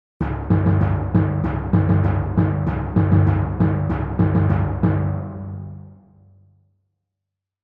7. Мелодия